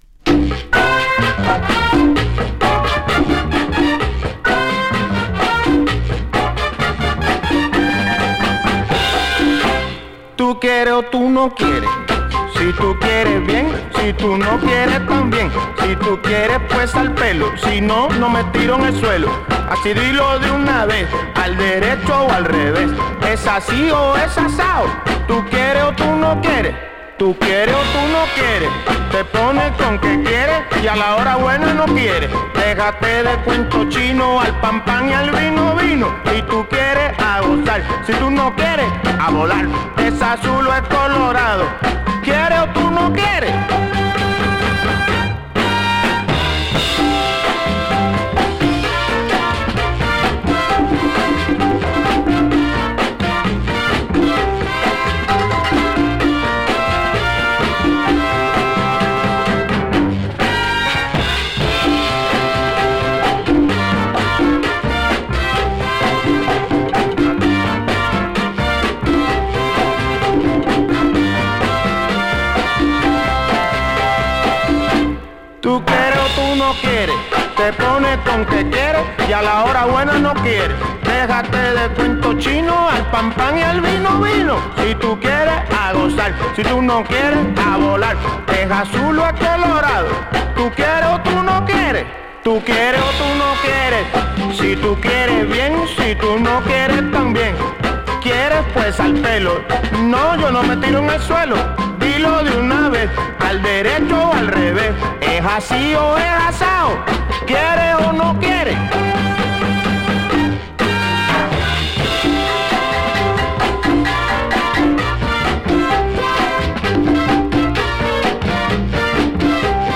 boogaloo/mod 45